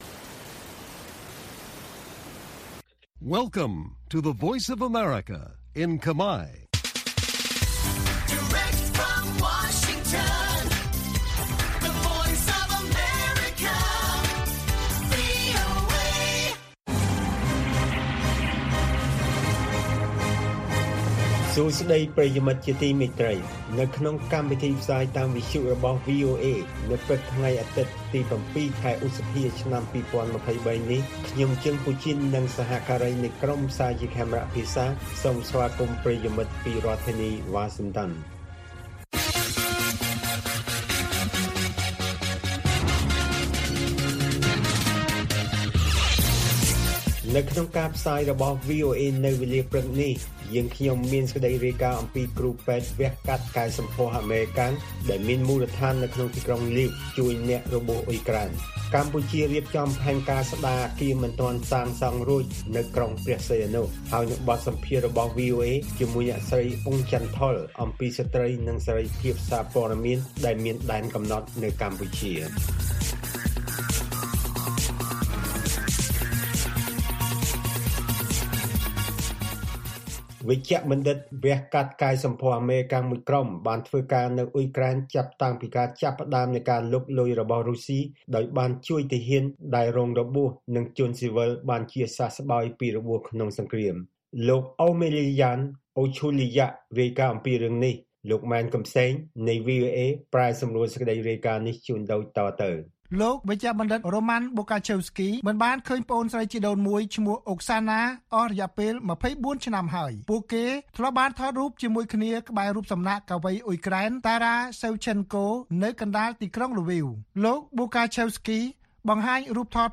ព័ត៌មានពេលព្រឹក ៧ ឧសភា៖